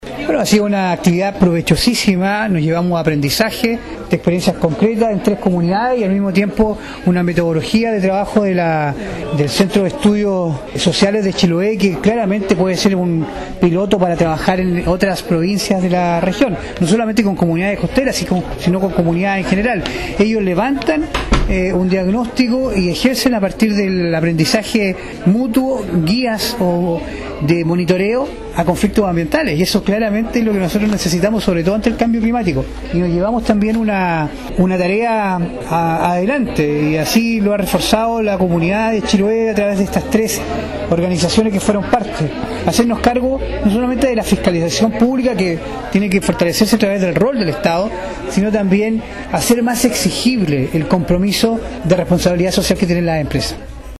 Por su parte el Consejero y Presidente de la Comisión de Medio Ambiente del Consejo Regional, Francisco Reyes, destacó el proyecto desarrollado por el Centro de Estudios Sociales de Chiloé, precisando que esta iniciativa podría replicarse en otras partes de la región, entendiendo que el cambio climático es una realidad: